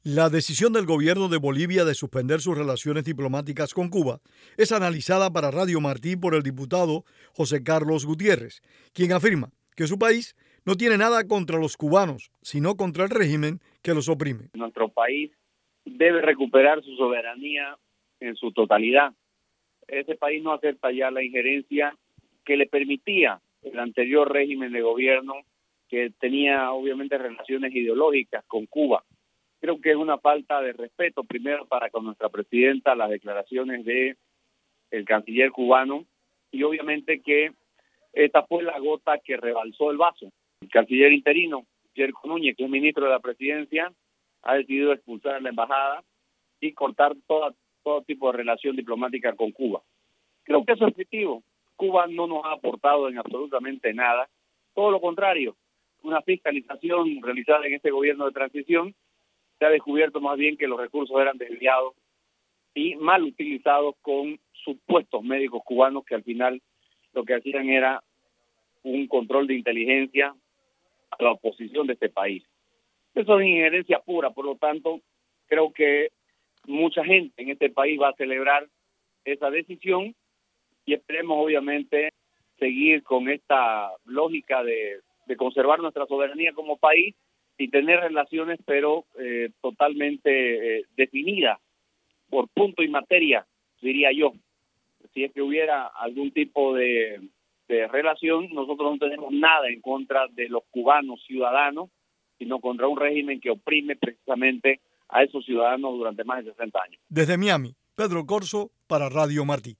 Entrevista al diputado boliviano José Carlos Gutiérrez